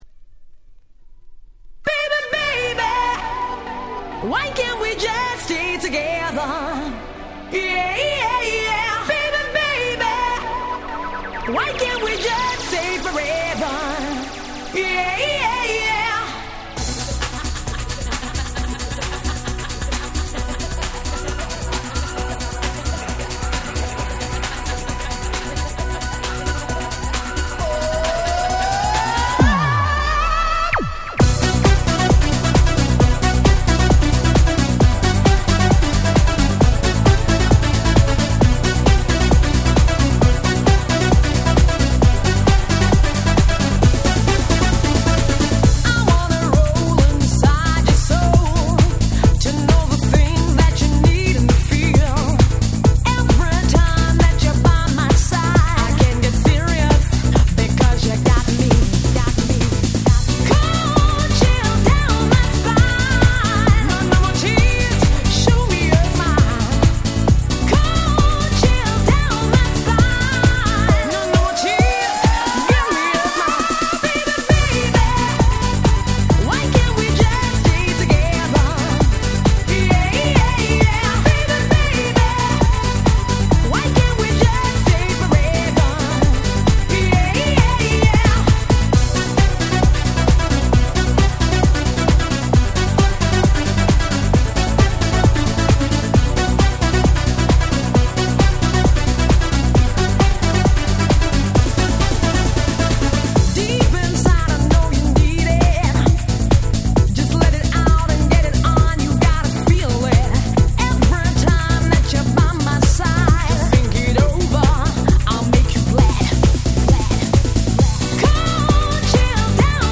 95' Super Hit Dance Pop !!
コロナ ダンスポップ ユーロ キャッチー系